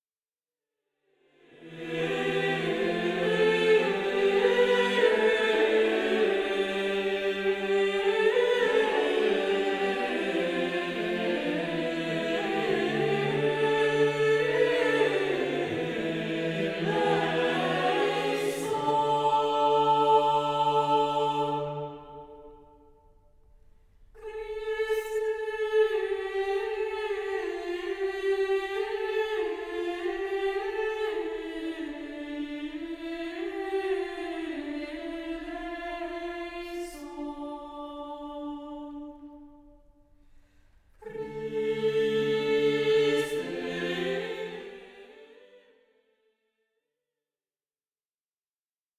Plain-chant et polyphonies du 14e siècle
Kyrie